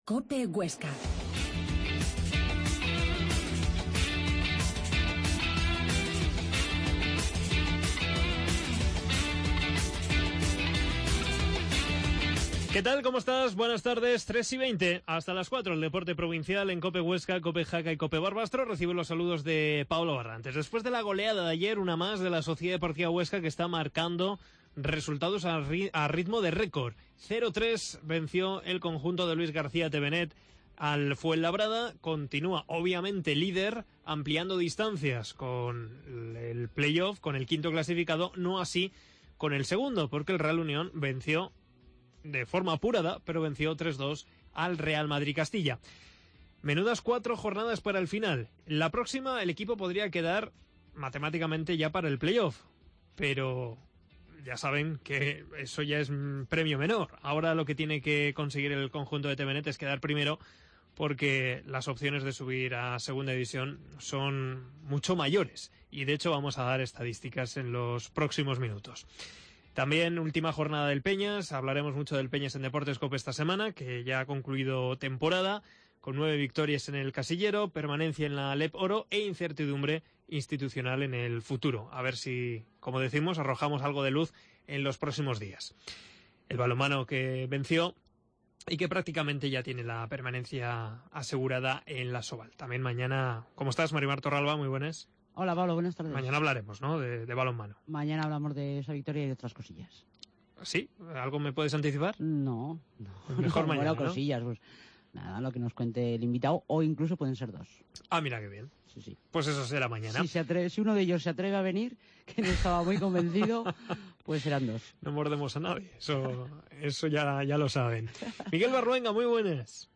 AUDIO: Tiempo de opinión de la SD Huesca con la presencia del director deportivo del Huesca Luís Helguera.